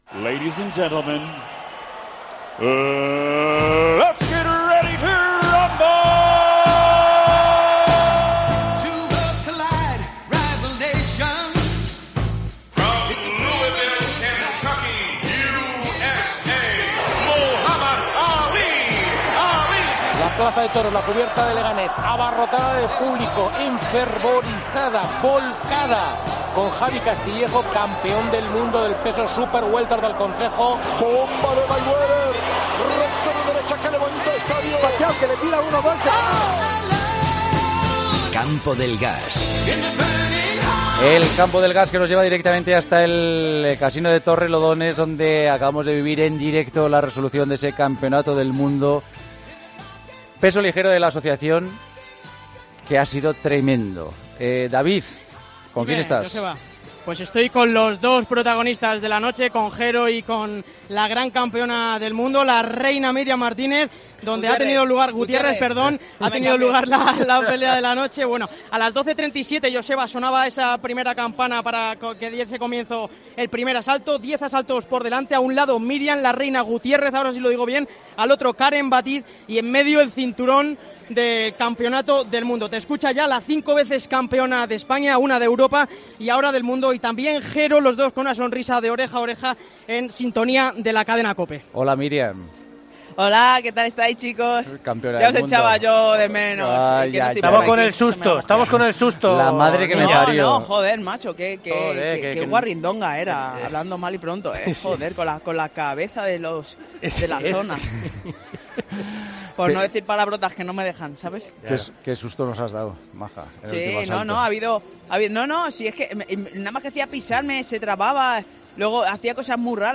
Combate en directo del campeonato del mundo WBA con la victoria de Miriam Gutiérrez